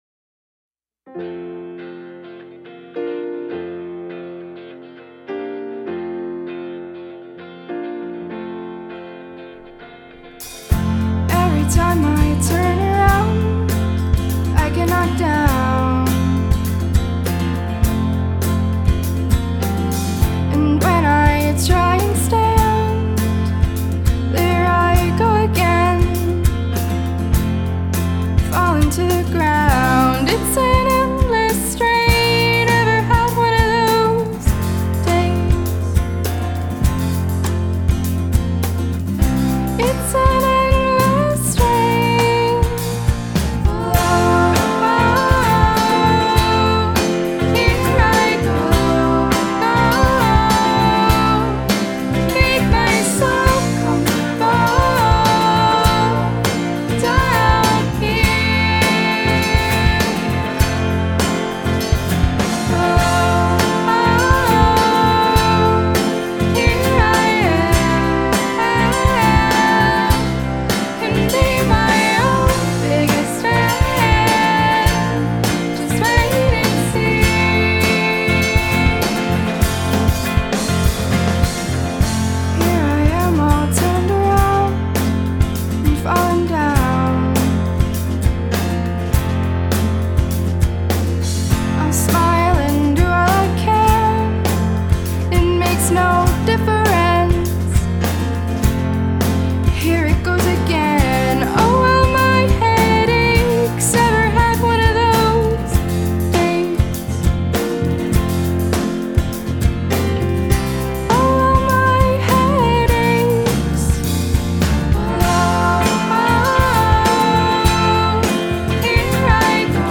simple, memorable
Lovely, potent stuff.